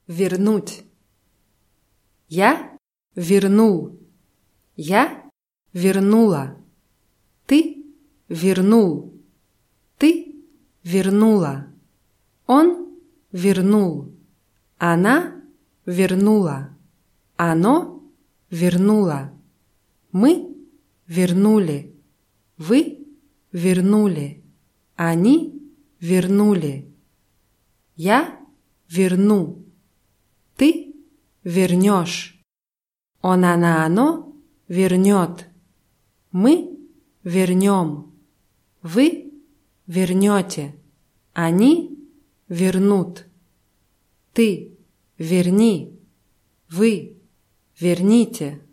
вернуть [wʲirnútʲ]